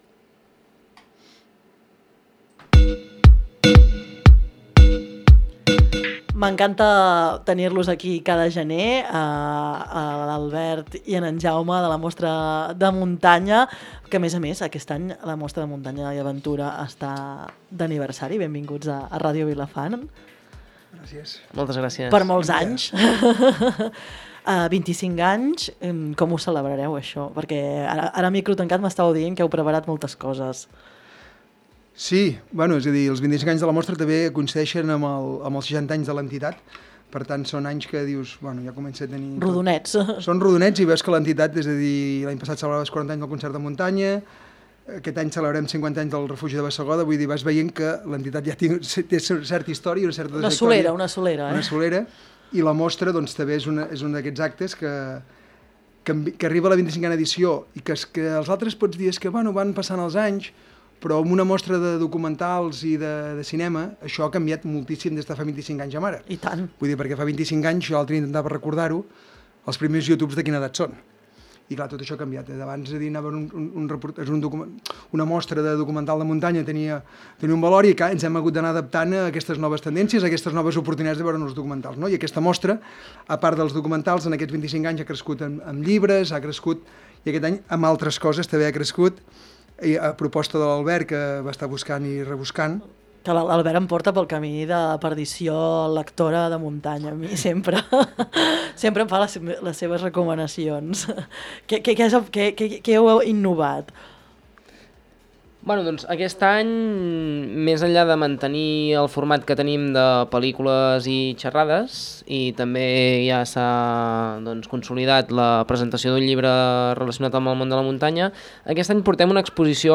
Durant la conversa s’ha explicat com serà aquesta nova edició i quines activitats i propostes més interessants inclou la programació d’enguany.